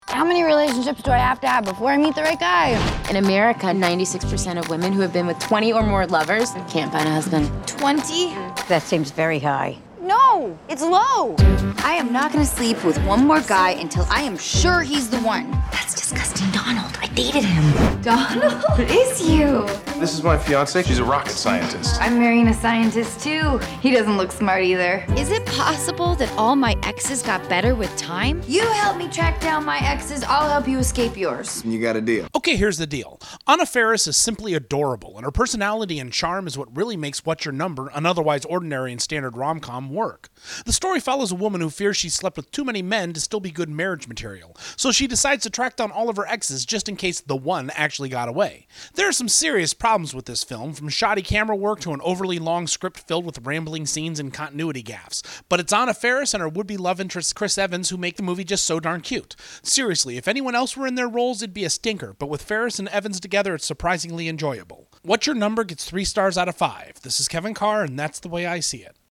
Movie Review: ‘What’s Your Number?’